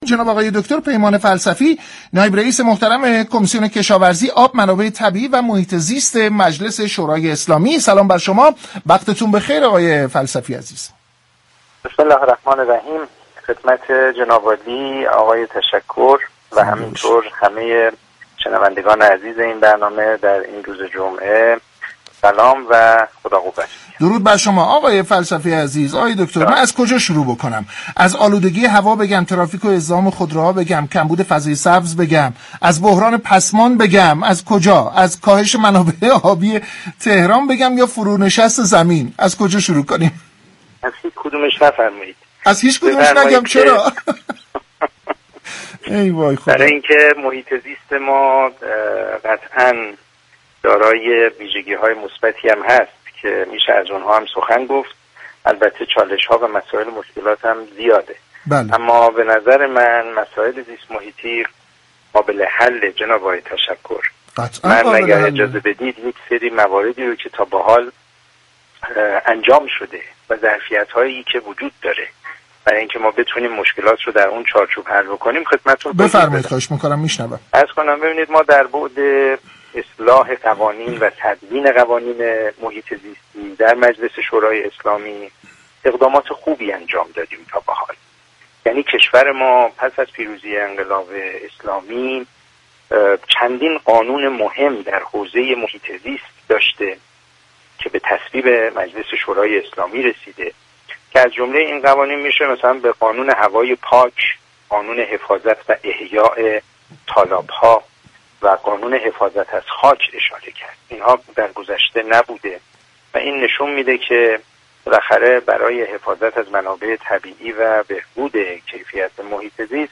پیمان فلسفی نایب رئیس كمیسیون كشاورزی، آب، منابع طبیعی و محیط زیست مجلس شورای اسلامی در گفت و گو با «اینجا تهران است»